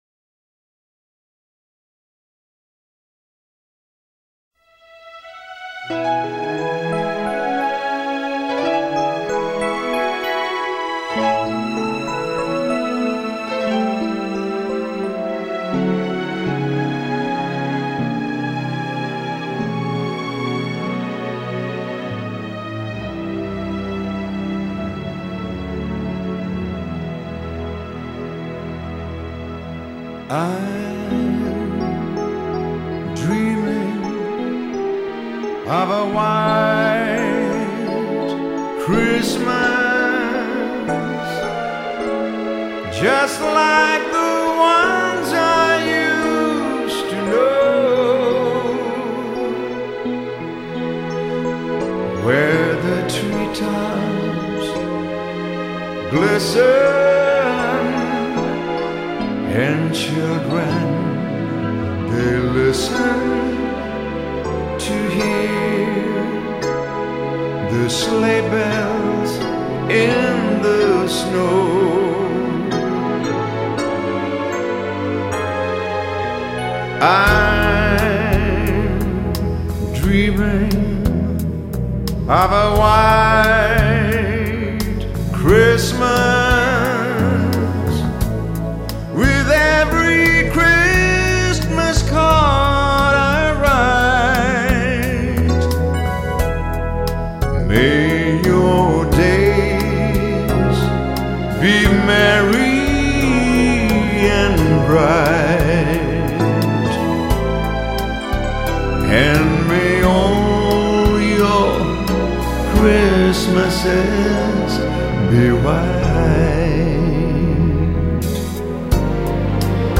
音乐类型： Pop, Christmas, PIANO　　　　　　　　　.